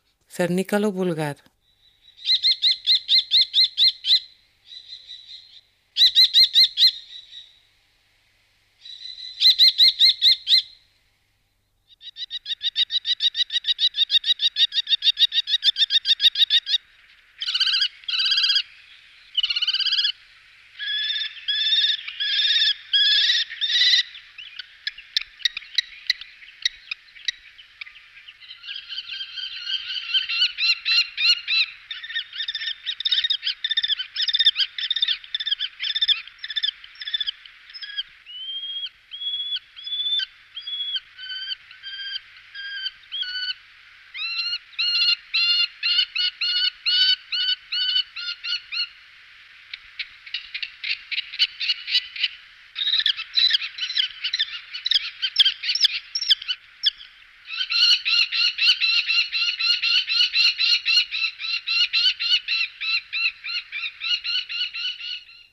Gheppio Europeo (Falco tinnunculus)
E’ un falco molto territoriale e vocifero quindi se un altro rapace prova a entrare nel suo territorio, lo attaccano, spesso in coppia minacciandolo con sonori ki-ki-ki-ki
FALCO TINNUNCULUS: dal latino, Tinnulus = squillante; cioè falco dal verso acuto.
0001-Cernicalo-vulgar.mp3